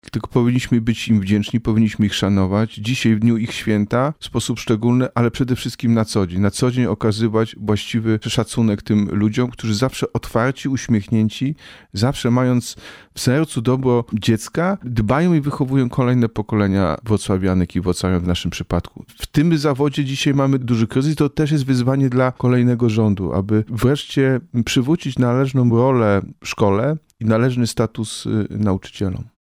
-Dzisiaj, gdyby nie nauczyciele-seniorzy pracujący w szkołach podstawowych, przedszkolach, szkołach średnich i technicznych, to nie byłoby ani wrocławskiej, ani polskiej edukacji. – dodaje prezydent.